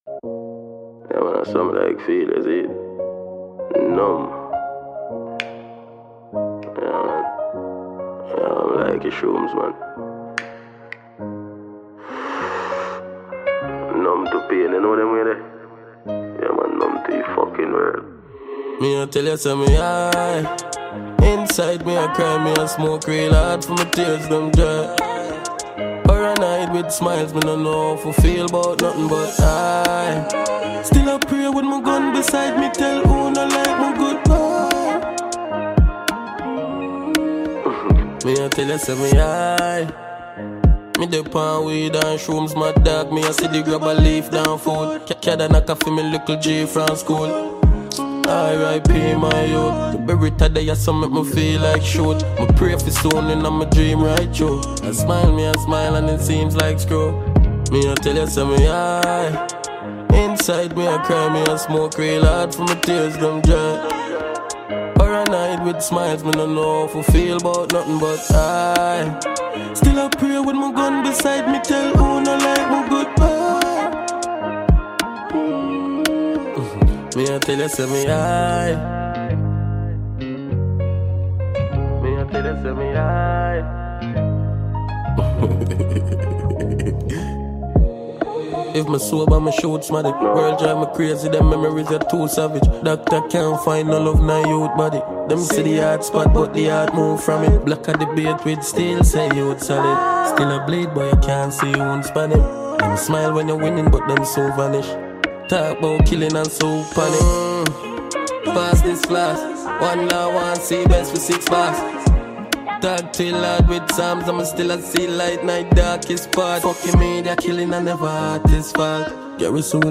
Jamaican award winning dancehall act